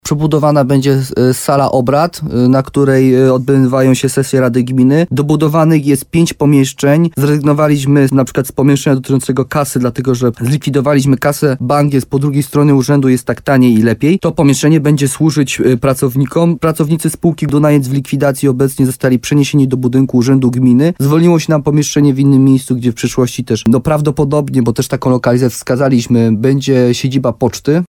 – Chcemy, aby teraz urząd był przede wszystkim dostosowany dla osób niepełnosprawnych – dodaje wójt gminy Gródek nad Dunajcem